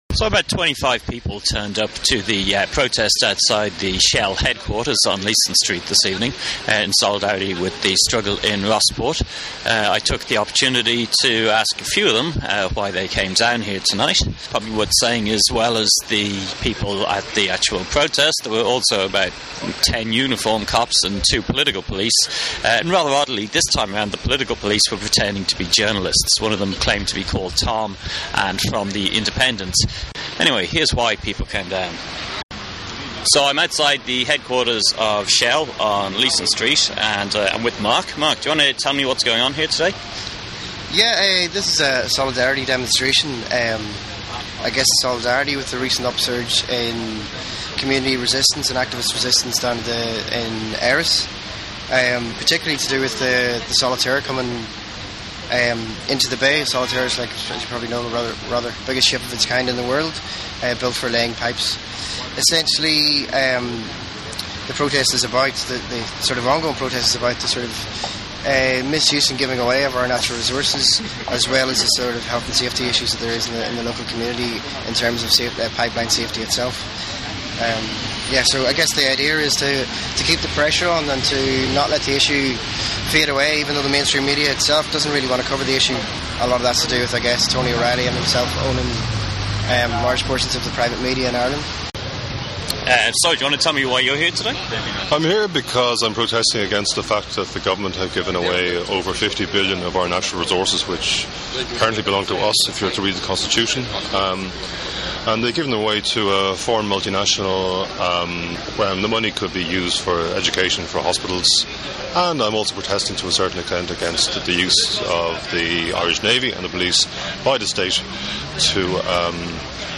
The audio is quick soundbytes with half a dozen of the participants.
Voices from the demonstration